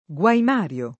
Guaimario [ gU aim # r L o ]